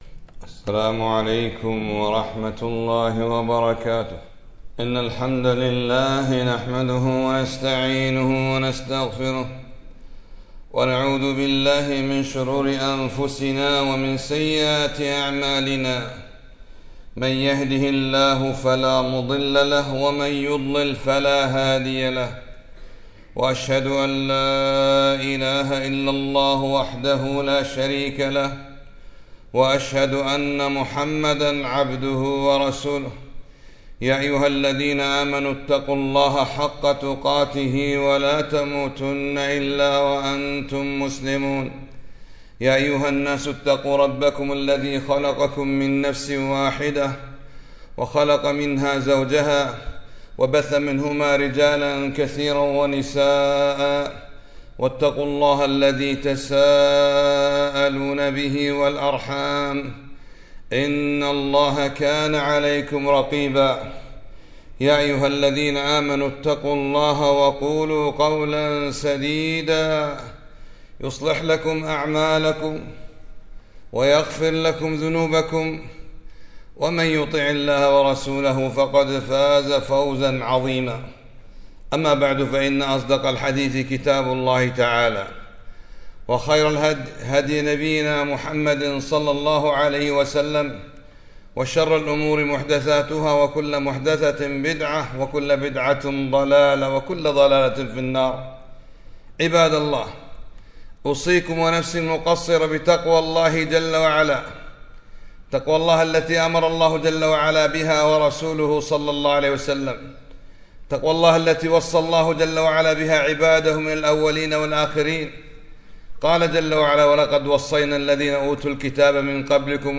خطبة - السبع المثاني والقرآن العظيم